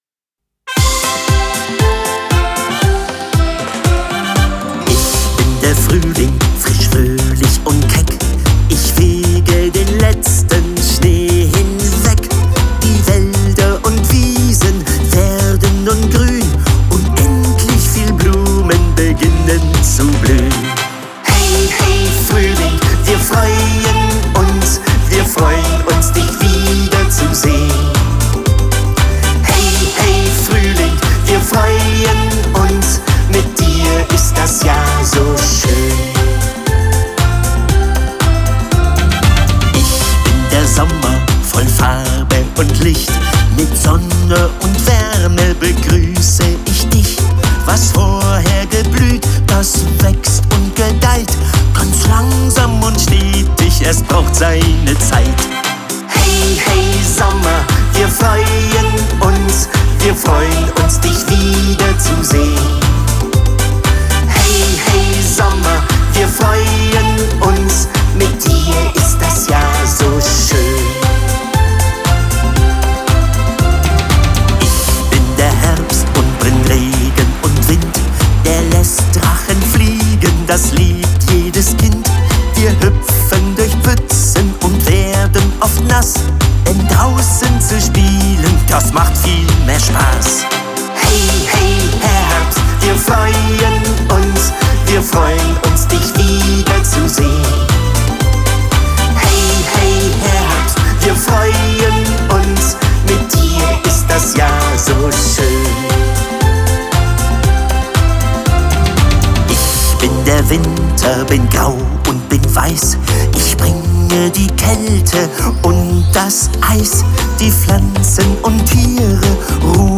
Seine schönsten Spiel-, Spaß- und Bewegungslieder